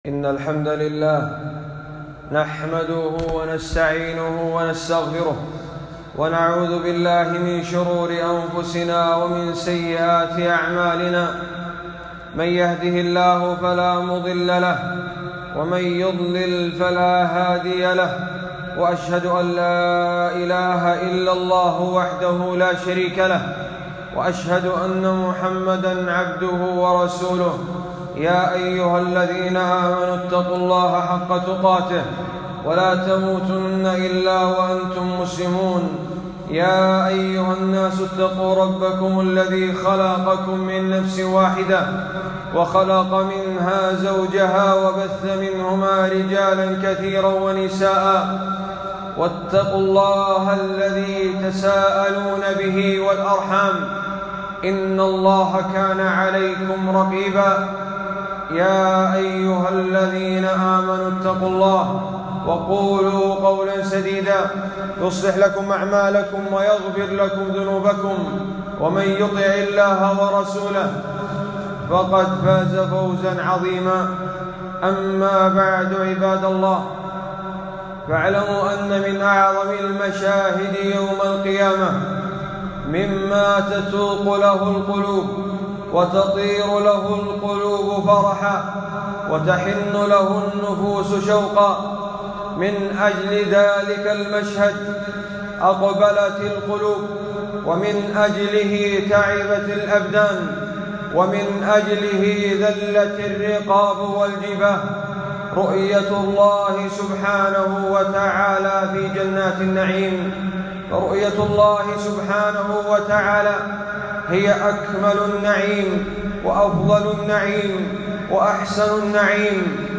خطبة - أكمل نعيم أهل الجنة